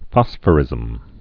(fŏsfə-rĭzəm)